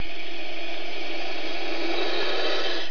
جلوه های صوتی
دانلود صدای حیوانات جنگلی 78 از ساعد نیوز با لینک مستقیم و کیفیت بالا